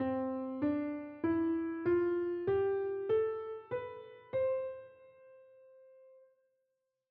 The example above shows C major scale.
C-Major-Scale-S1.wav